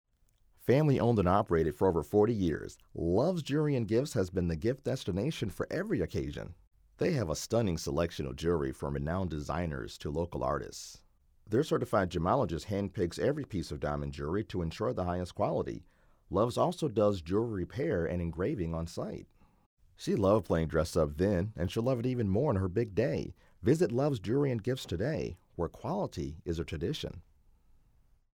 Voiceovers for Radio & TV Commercials
Commercial Sample 1